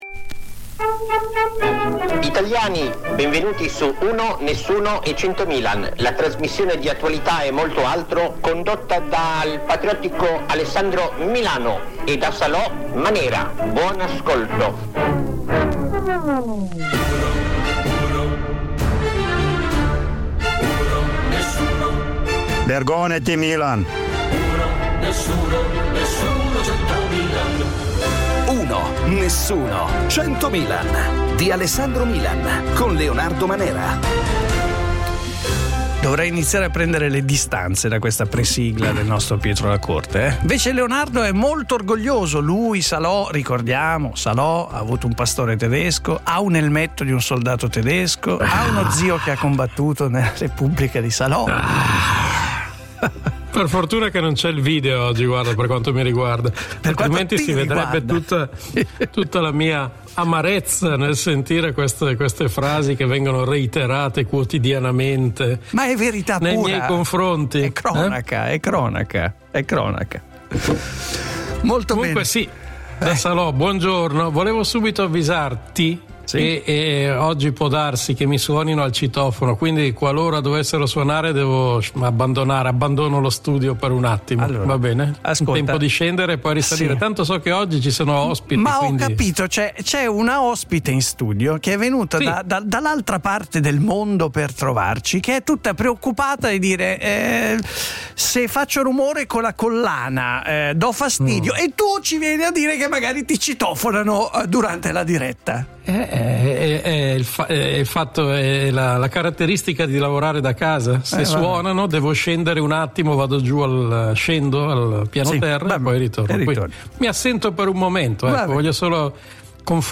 1 Napoli, si invoca l'esercito per fermare la violenza armata giovanile Play Pause 2h ago Play Pause Riproduci in seguito Riproduci in seguito Liste Like Like aggiunto — Ospite in studio della trasmissione la scrittrice Laura Imai Messina che ci porterà con i suoi racconti in Giappone, Paese dove risiede da molti anni ormai. A seguire ci occupiamo della escalation di violenza giovanile armata a Napoli che sta portando alcuni operatori e magistrati a invocare la presenza dell'esercito.